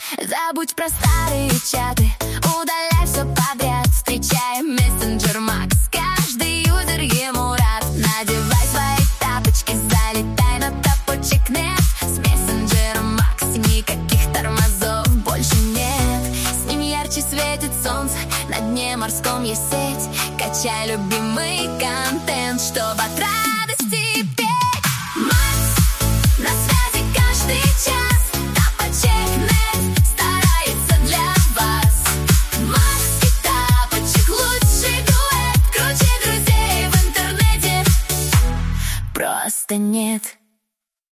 Прямая ссылка на гимн, если кому надо: